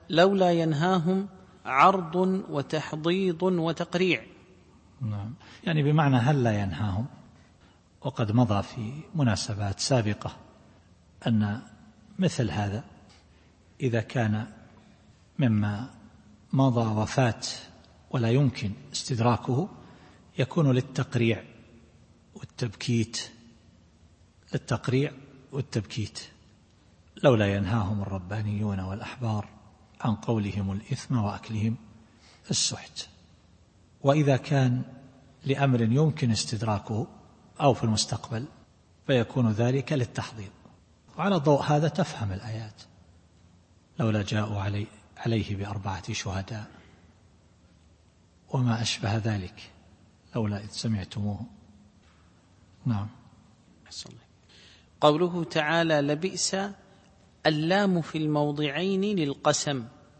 التفسير الصوتي [المائدة / 63]